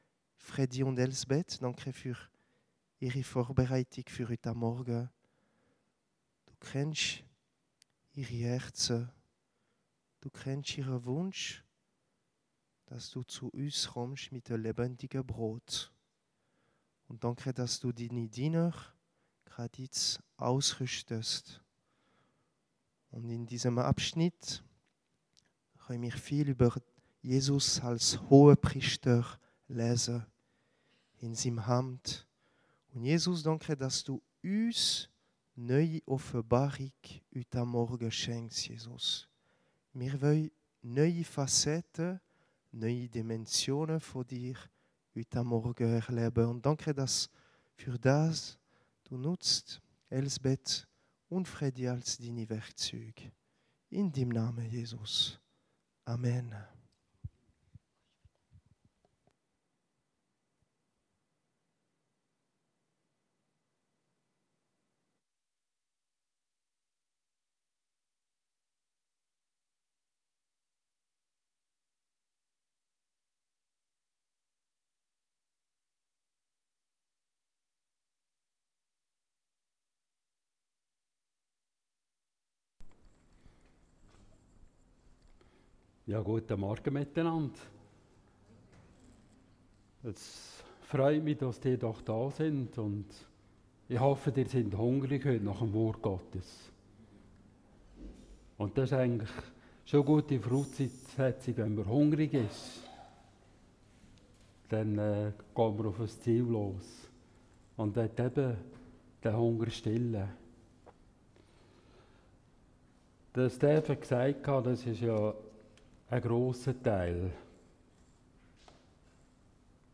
Predigten - Korps Aarau